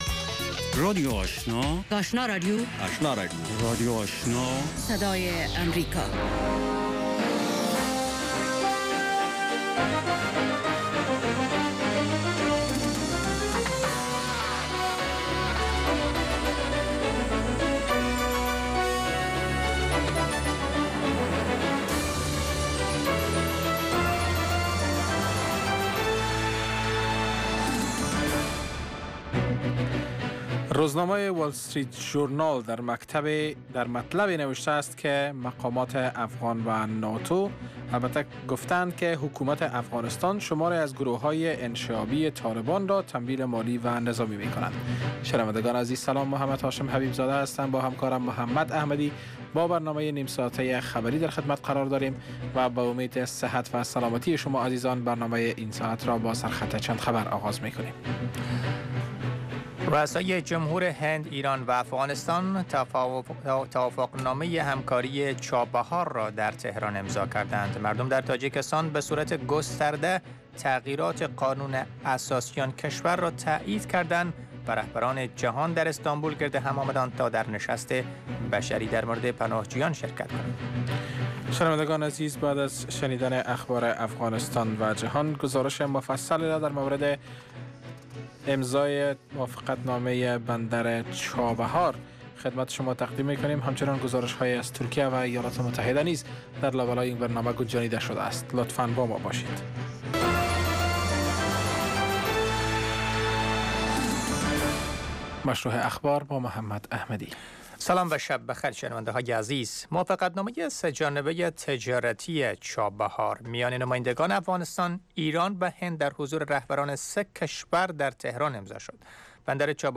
در اولین برنامه خبری شب، خبرهای تازه و گزارش های دقیق از سرتاسر افغانستان، منطقه و جهان فقط در سی دقیقه.